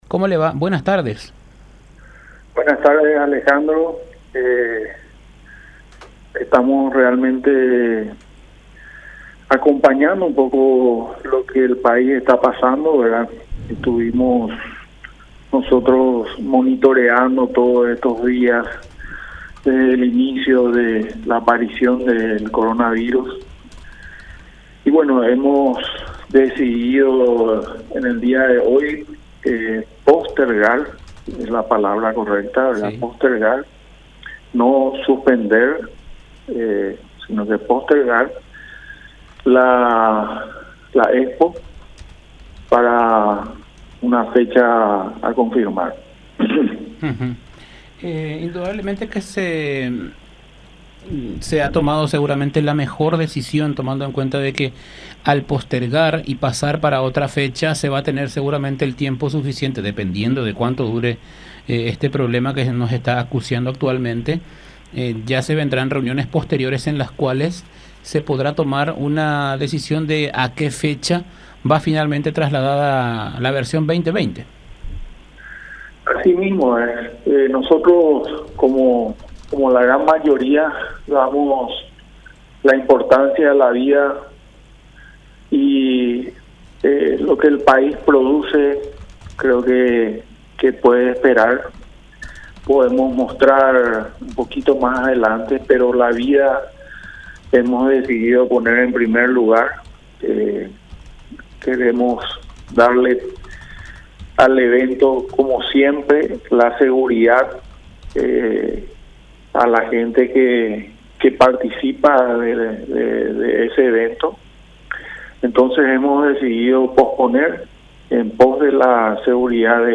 en comunicación con La Unión R800AM.